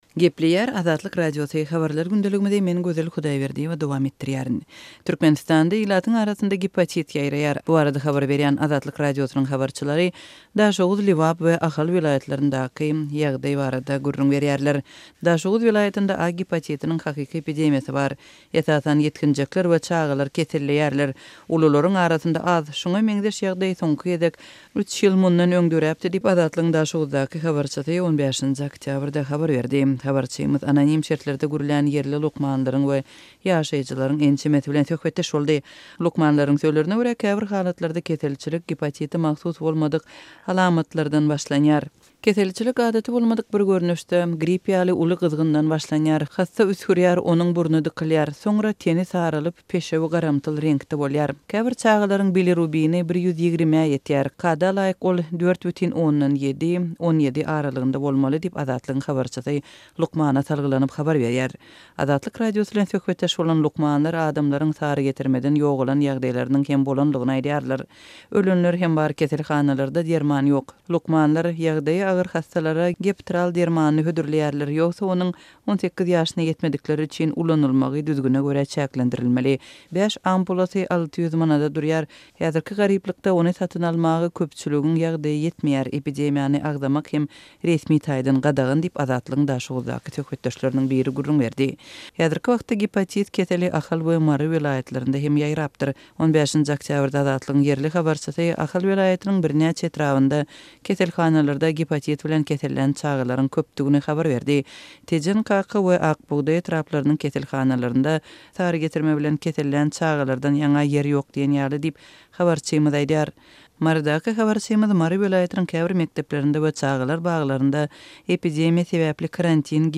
Türkmenistanda ilatyň arasynda gepatit ýaýraýar. Azatlyk Radiosynyň habarçylary Daşoguz, Lebap we Ahal welaýatlaryndaky ýagdaý barada habar berýärler.